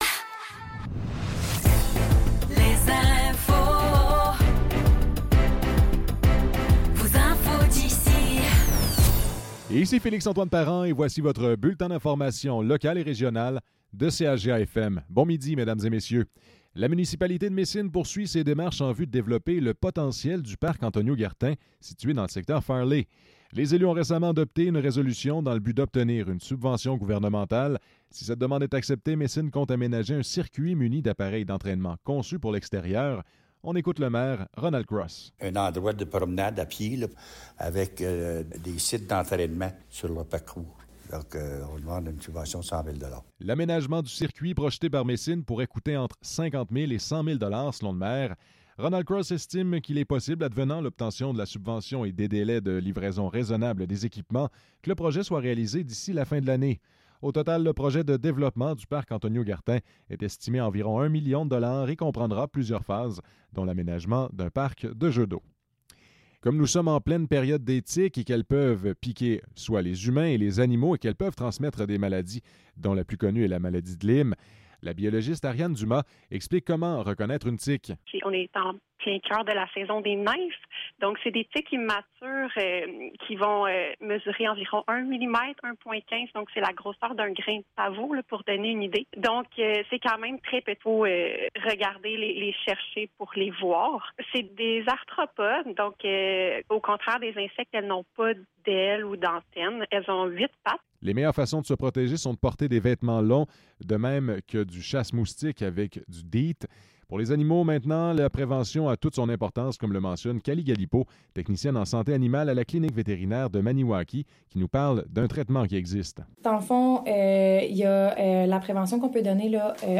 Nouvelles locales - 14 juin 2024 - 12 h